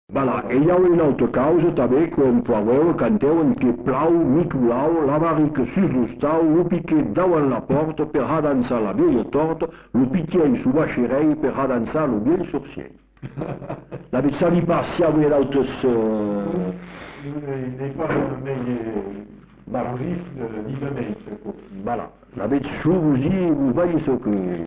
Lieu : Bazas
Genre : forme brève
Type de voix : voix d'homme
Production du son : récité
Classification : formulette enfantine